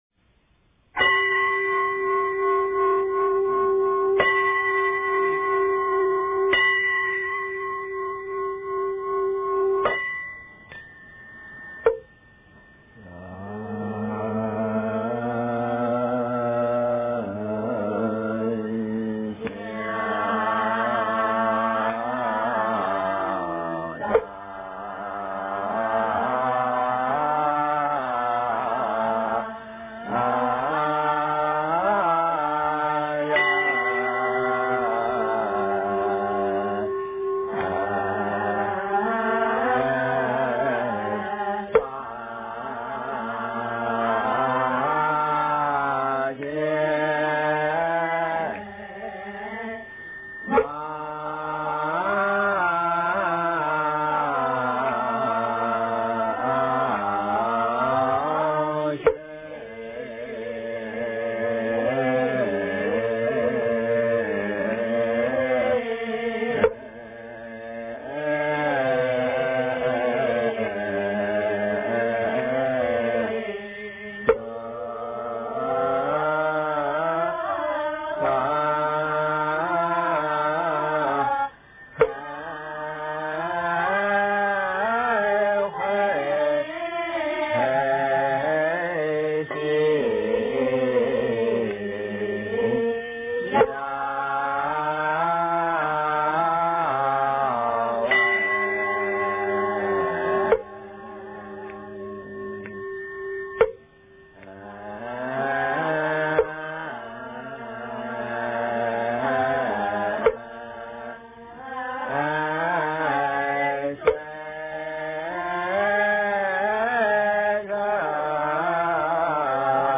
金刚经--僧团 经忏 金刚经--僧团 点我： 标签: 佛音 经忏 佛教音乐 返回列表 上一篇： 金刚经--僧团 下一篇： 静思佛号(男女合唱版)--新韵传音 相关文章 大悲心陀罗尼--佛教音乐 大悲心陀罗尼--佛教音乐...